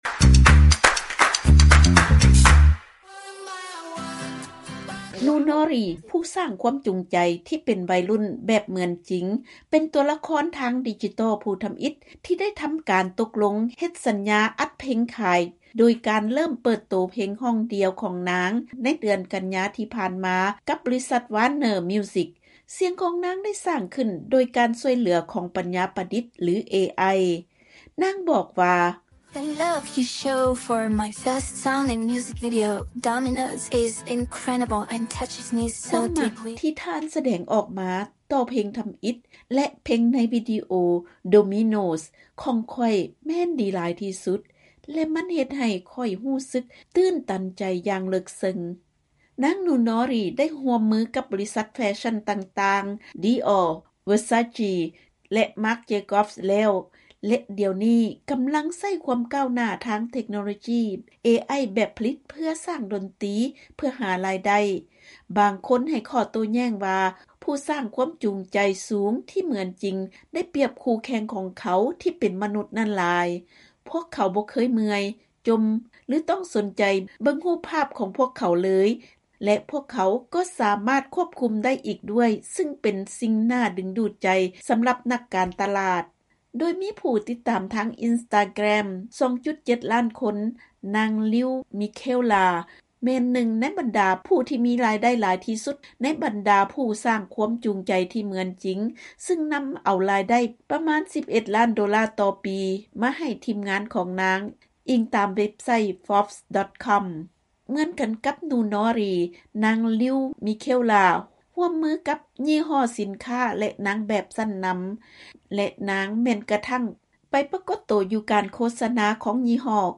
ເຊີນຮັບຟັງລາຍງານ ກ່ຽວກັບຜູ້ສ້າງແຮງຈູງໃຈແບບເໝືອນຈິງ ທີ່ໄດ້ຮັບຄວາມນິຍົມຢ່າງວອງໄວ ສ້າງໂດຍປັນຍາປະດິດແບບຜະລິດ